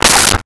Dog Barking 05
Dog Barking 05.wav